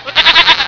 GOAT.WAV